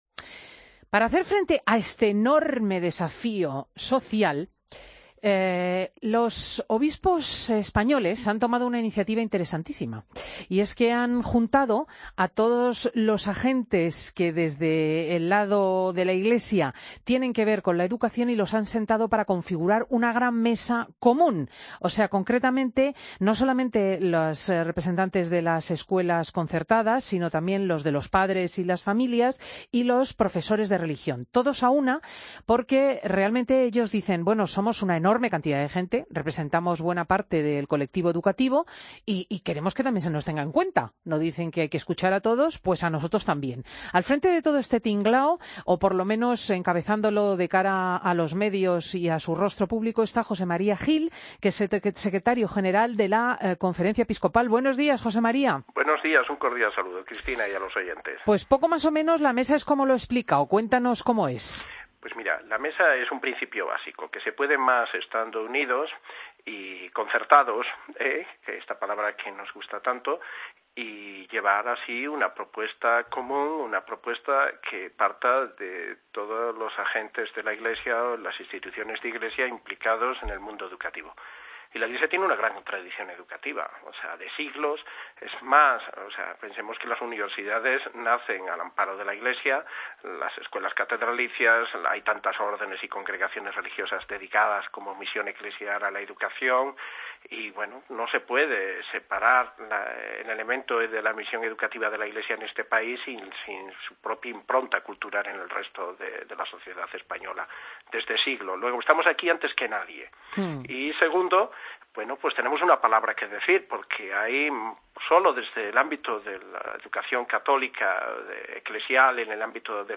AUDIO: Escucha la entrevista a José María Gil Tamayo en Fin de Semana